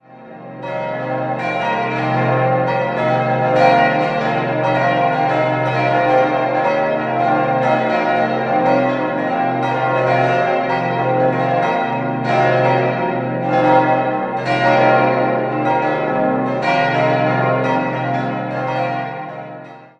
5-stimmiges Geläute: c'-es'-f'-g'-b' Alle Glocken wurden von der Gießerei Petit&Edelbrock in Gescher (Westfalen) gegossen.